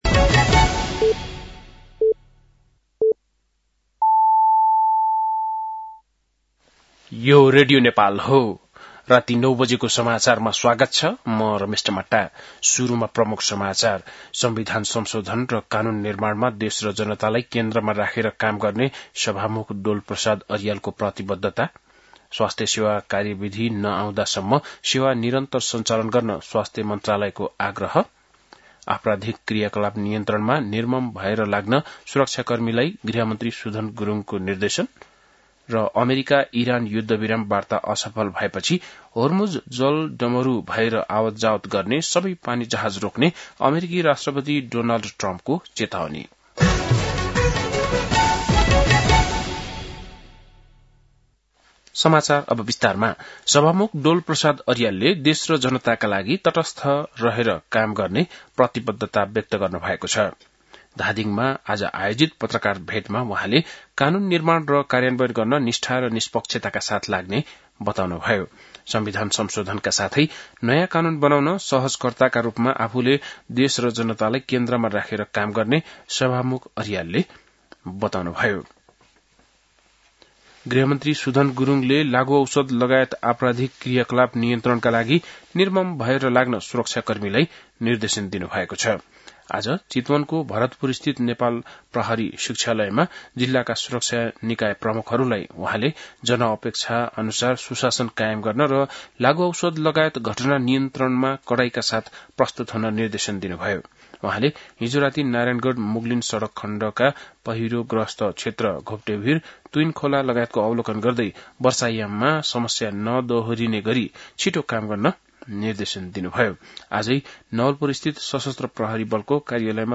बेलुकी ९ बजेको नेपाली समाचार : २९ चैत , २०८२
9-pm-nepali-news-12-29.mp3